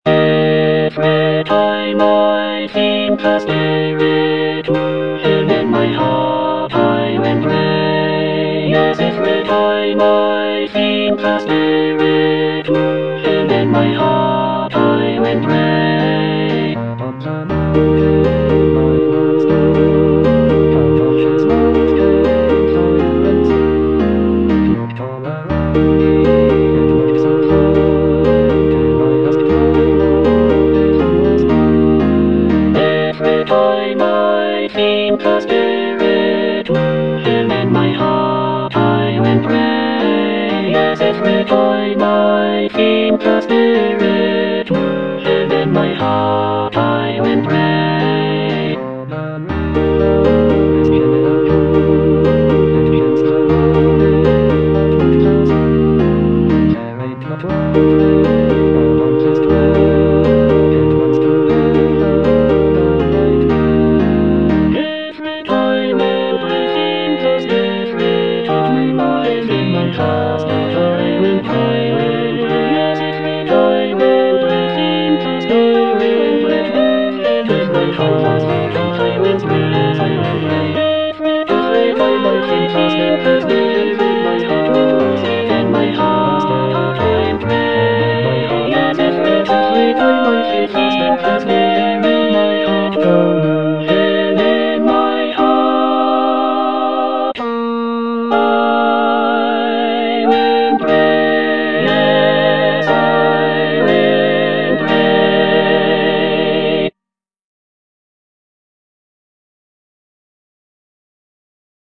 (All voices)
traditional African-American spiritual